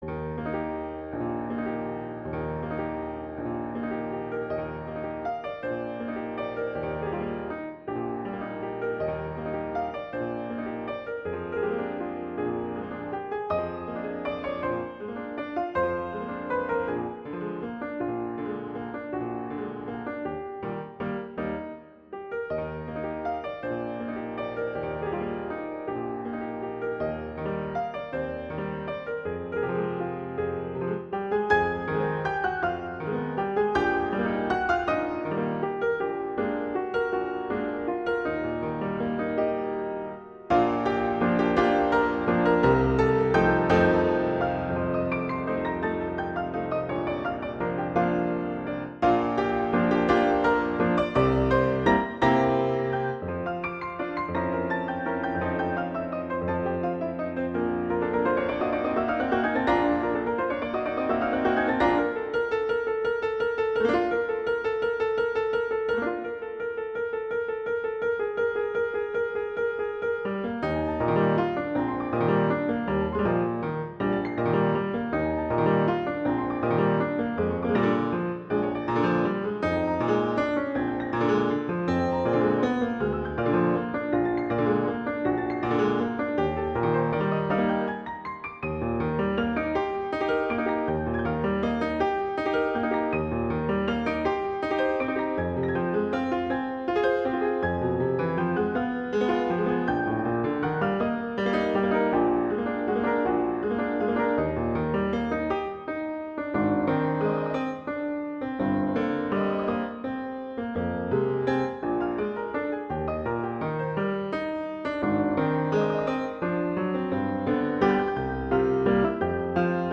classical, wedding, festival, love
Eb major
♩=160 BPM